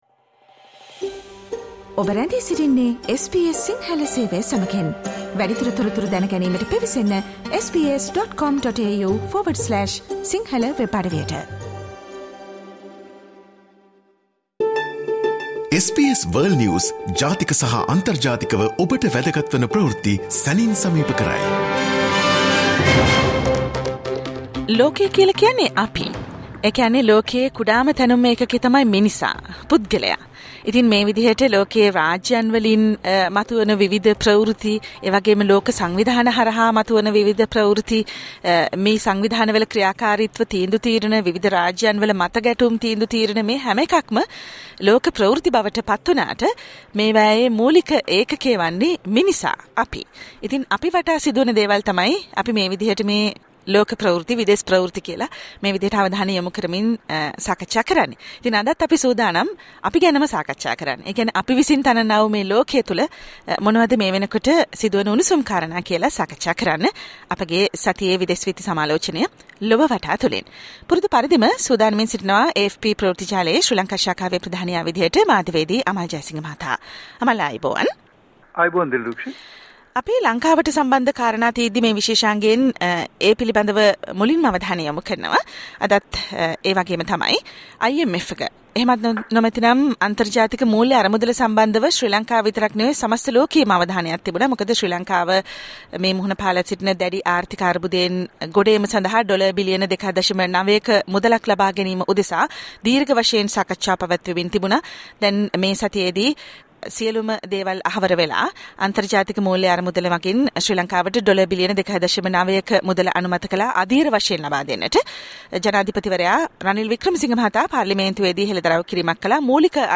listen to the SBS Sinhala Radio weekly world News wrap every Friday Share